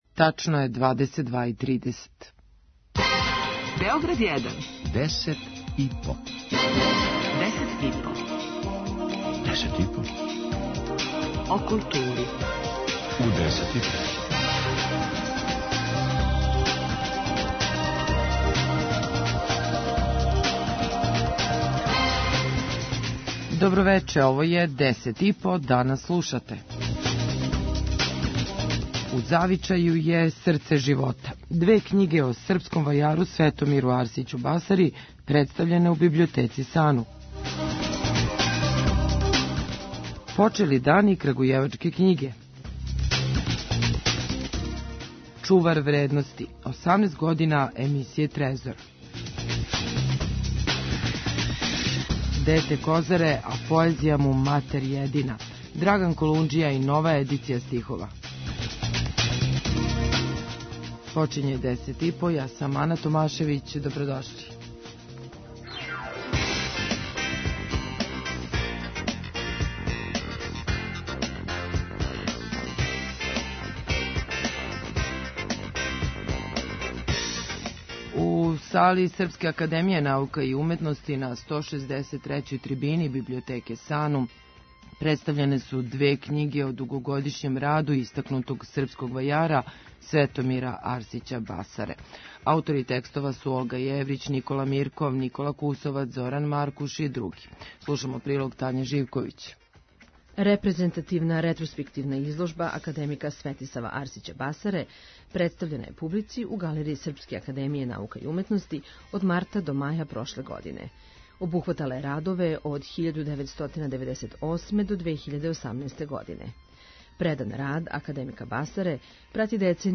преузми : 5.40 MB Десет и по Autor: Тим аутора Дневни информативни магазин из културе и уметности. Вести, извештаји, гости, представљање нових књига, концерата, фестивала, репортерска јављања са изложби, позоришних и филмских премијера и најактуелнијих културних догађаја.